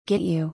子音 + y- make_you[méɪk_ jʊ]「メイクユー→メイキュー」 get_you[gét_jʊ]「ゲットユー→ゲッドゥュー」